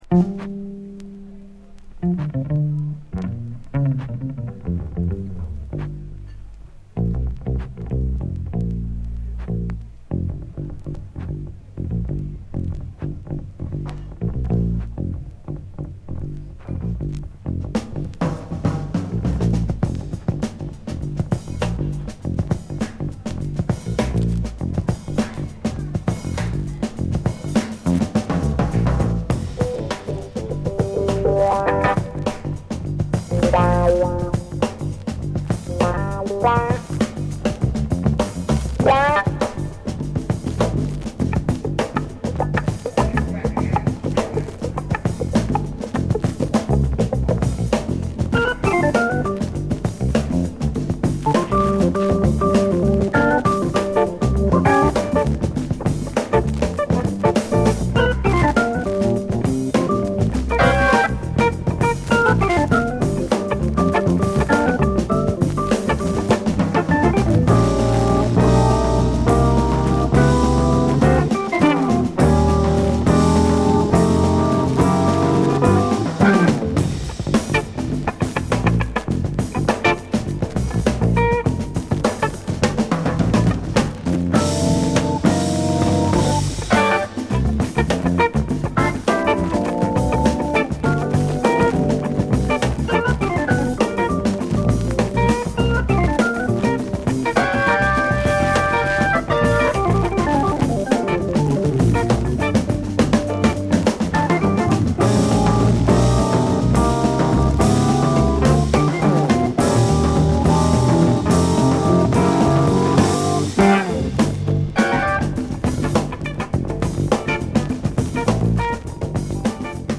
72年にL.A.で行われた傑作ライブ！
両サイド冒頭に薄っすらヒートダメージ。開始から数回転少々音に出ます。